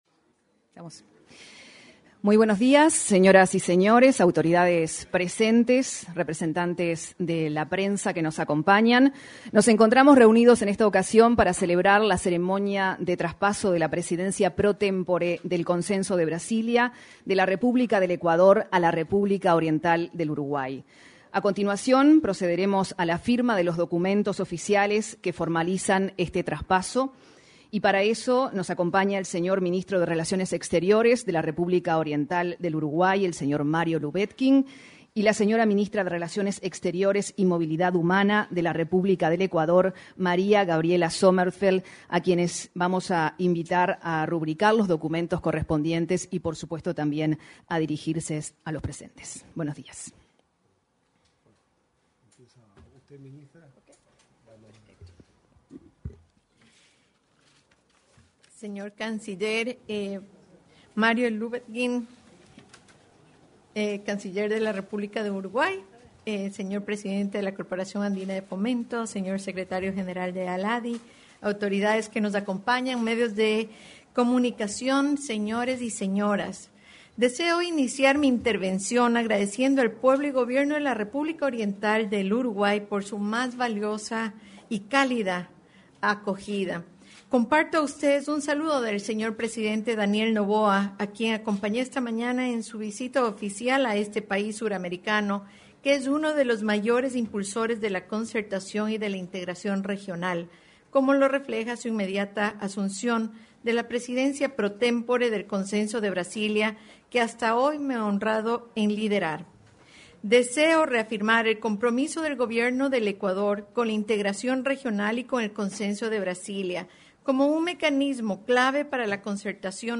Palabras de cancilleres de Uruguay y Ecuador en traspaso de presidencia temporal del Consenso de Brasilia
Palabras de cancilleres de Uruguay y Ecuador en traspaso de presidencia temporal del Consenso de Brasilia 19/08/2025 Compartir Facebook X Copiar enlace WhatsApp LinkedIn La ministra de Relaciones Exteriores y Movilidad Humana de Ecuador, Gabriela Sommerfeld, y el canciller de Uruguay, Mario Lubetkin, se expresaron durante la ceremonia de traspaso de la presidencia temporal del Consenso de Brasilia.